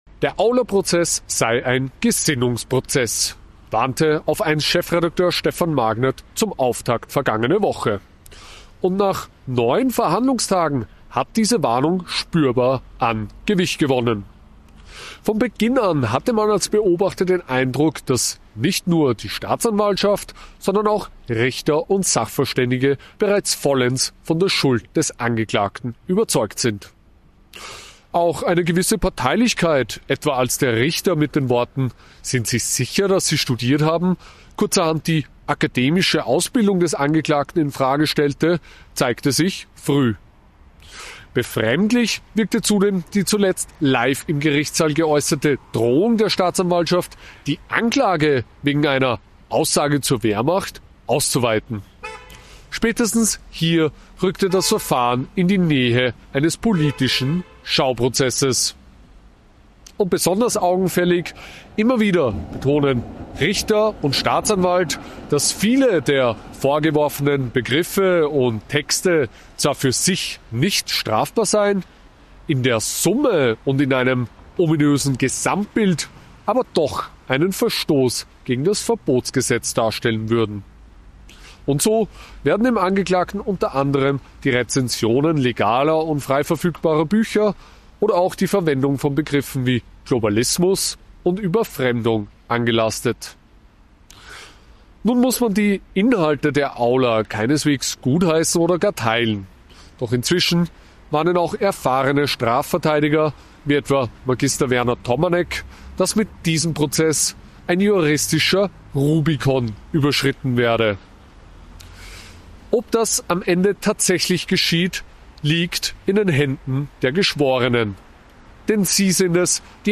Kommentar aus Graz.